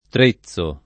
Trezzo [ tr %ZZ o ]